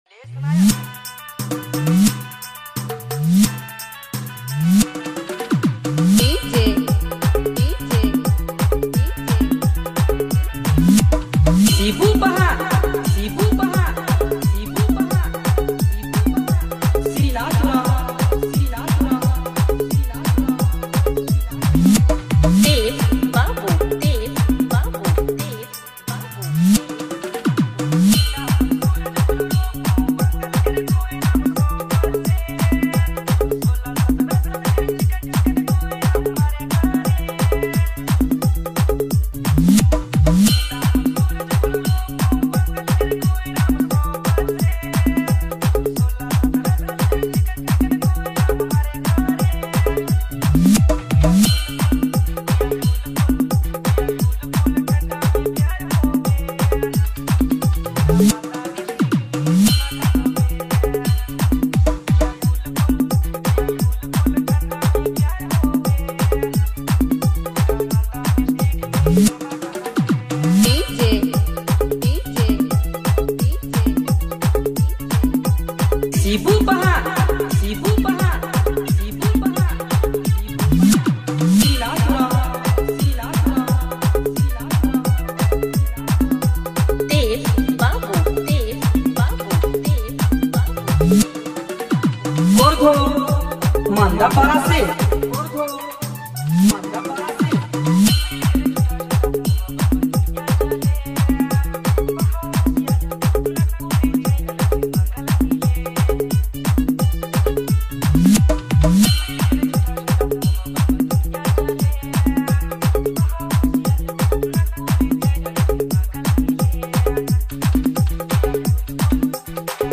Dj Remixer